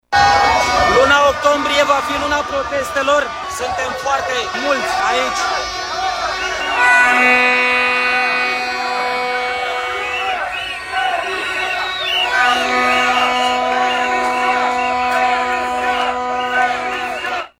Câteva mii de protestatari se deplasează de la această oră (16,00) într-un marș de protest din Piața Universității către Piața Victoriei, în fața sediului Guvernului.
Manifestanții poartă steaguri tricolore și pancarte și scandează lozinci antiguvernamentale. Ei sunt nemulțumiți, între altele, de creșterea prețurilor la energia electrică și gaze naturale.
stiri-2-oct-vox-protest-1.mp3